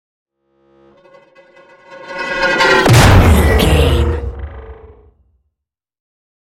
Sci fi whoosh to hit horror
Sound Effects
Atonal
dark
futuristic
intense
woosh to hit